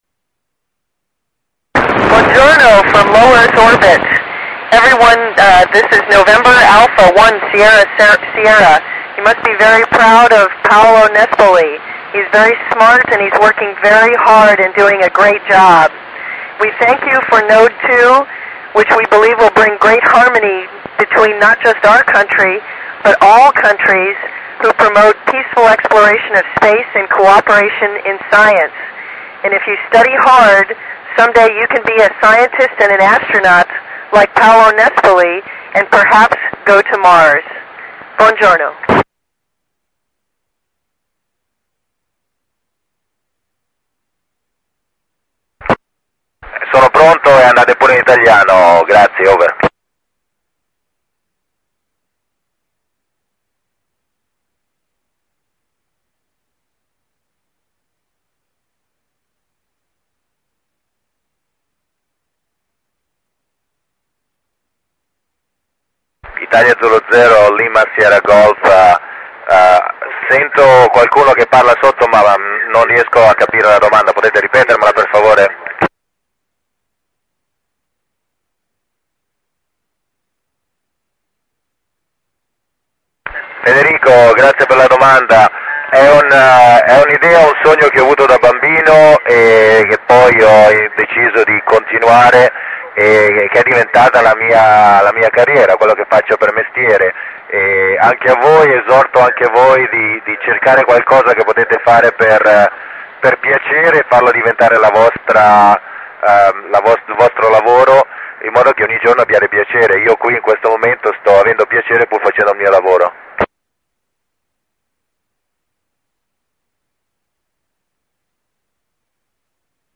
29 OTTOBRE 2007, time 09:23 ITIS Pratola Peligna, ricezione ISS
A bordo l'astronauta PAOLO NESPOLI che risponde alle domande poste dall'universita' dell'Aquila e dall'IIS "Deambrosis - Natta" in Sestri Levante , RX TS-2000, Antenna Turnstile, software tracking ORBITRON, ricezione perfetta segnali S9+10 db.